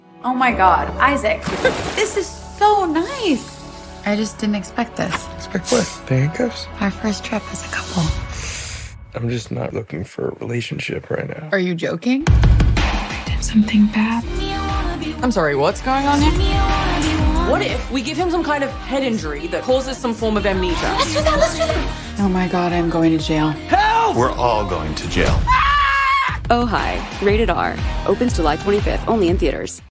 Hi!TV Spots Download This Spot